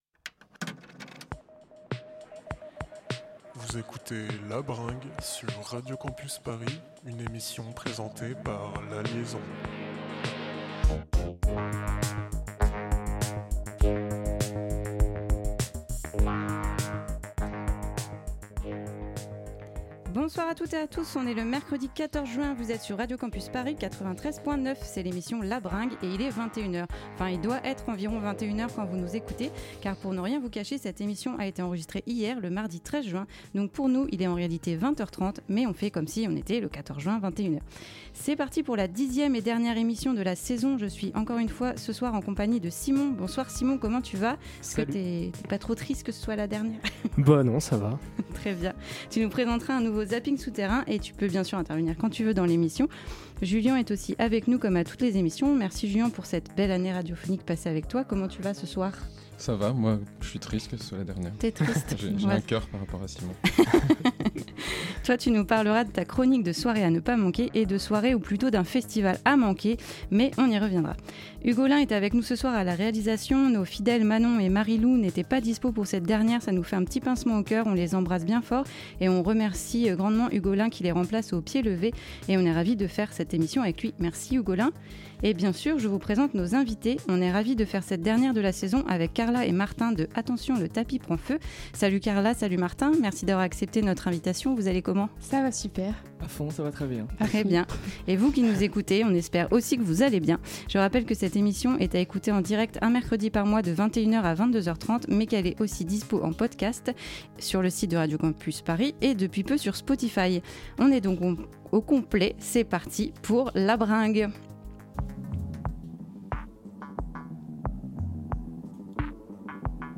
Pop & Rock
Au programme de l’émission 1h30 de conversation avec les invitées et un live en fin d'émission.